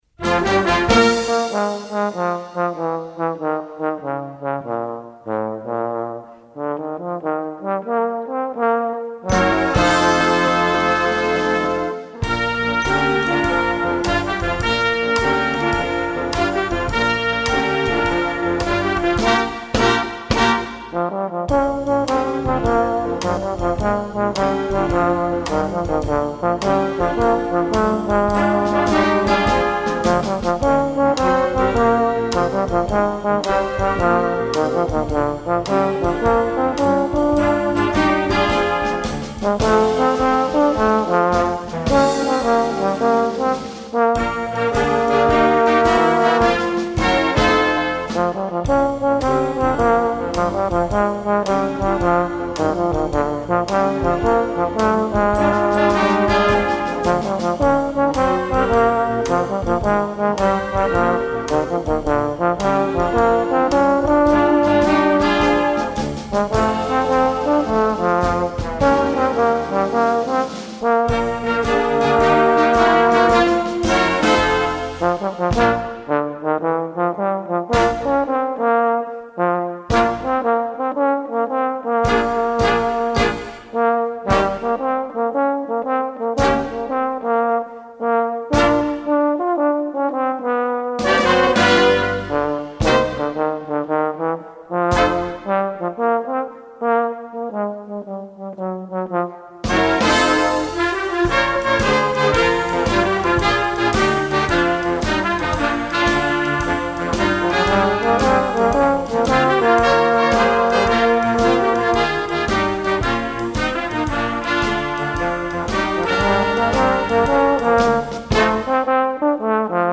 Voicing: Euphonium Solo w/ Band